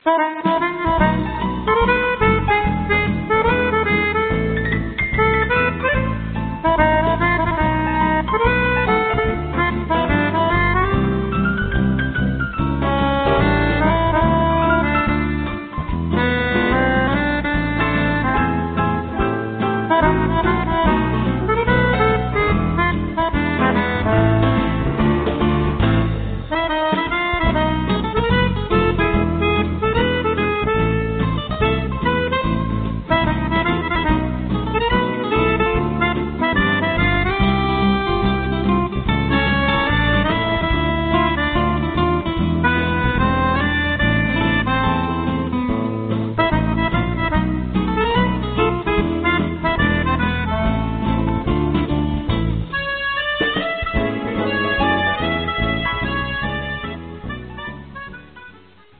بی کلام فان